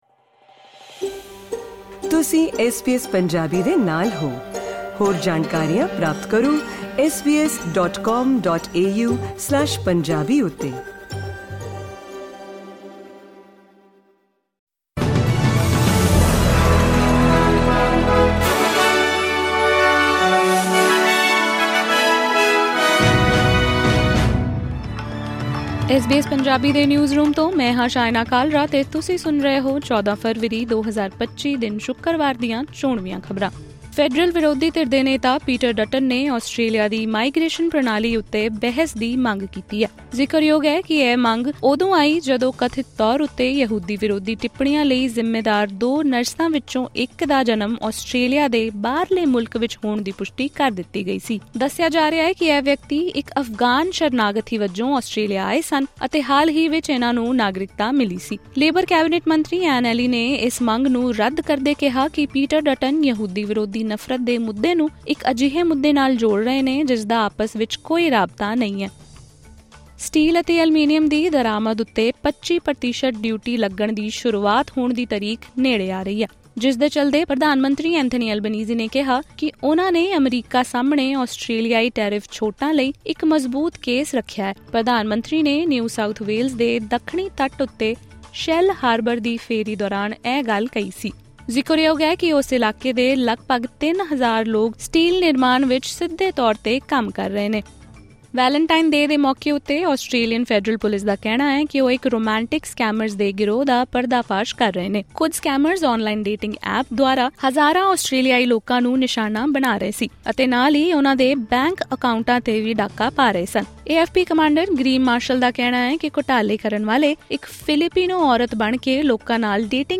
ਖ਼ਬਰਨਾਮਾ: ਯਹੂਦੀ ਵਿਰੋਧੀ ਵੀਡੀਓ ਤੋਂ ਬਾਅਦ ਪੀਟਰ ਡਟਨ ਵਲੋਂ ਇਮੀਗ੍ਰੇਸ਼ਨ ਪ੍ਰਣਾਲੀ ਉੱਤੇ ਬਹਿਸ ਦੀ ਮੰਗ